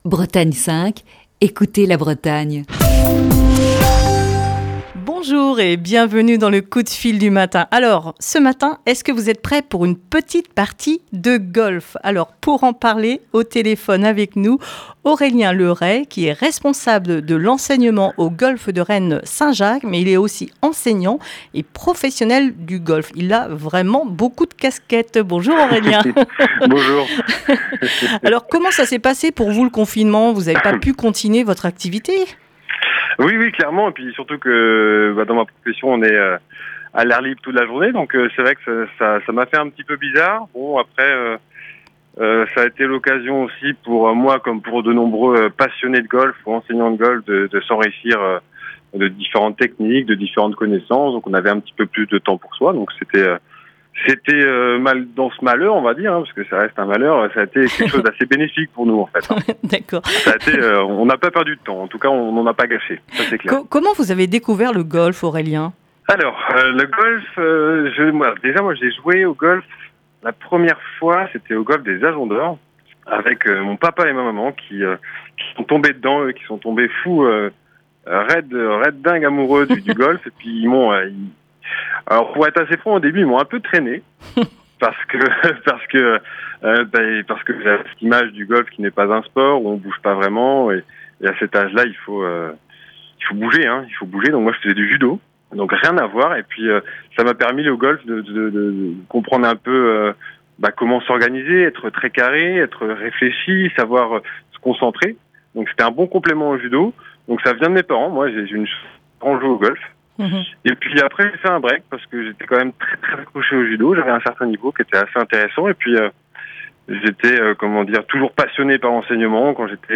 (Émission diffusée le 24 juin 2020).